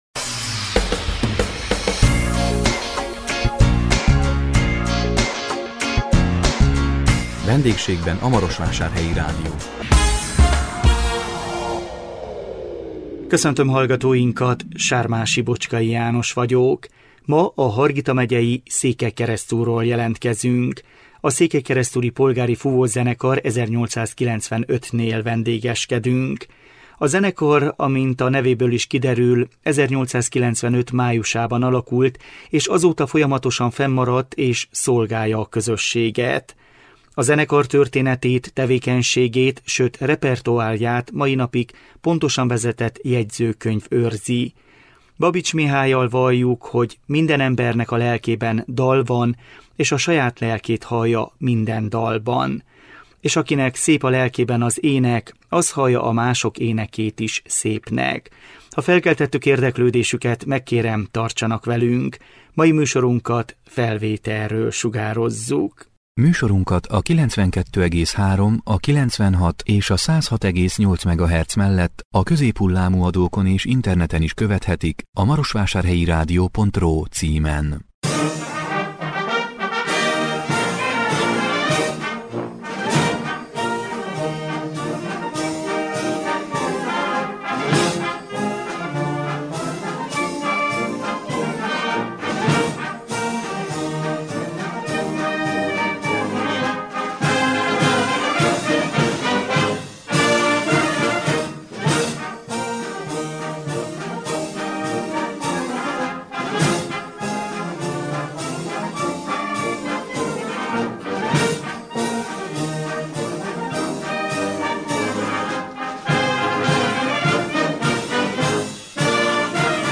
A 2022 február 17-én jelentkező VENDÉGSÉGBEN A MAROSVÁSÁRHELYI RÁDIÓ című műsorunkkal a Hargita megyei Székelykeresztúrról jelentkeztünk, a Székelykeresztúri Polgári Fúvószenekar 1895-nél vendégeskedtünk.